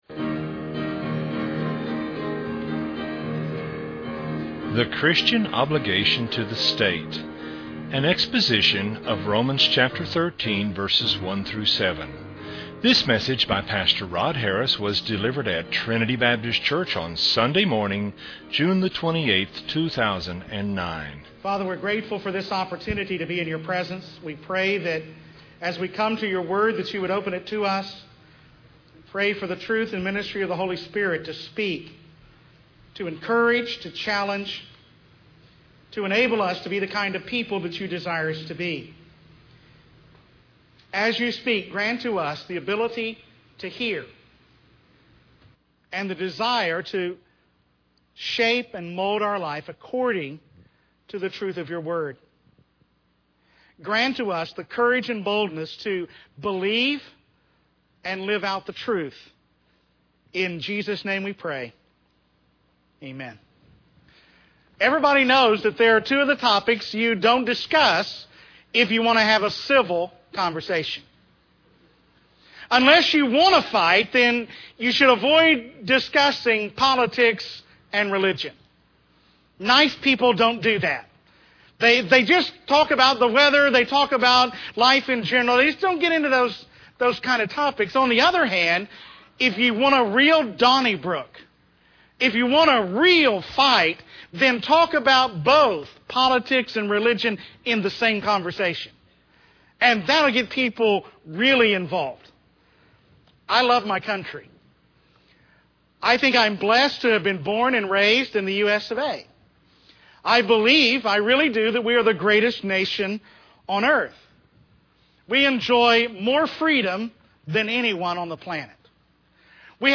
delivered at Trinity Baptist Church on Sunday morning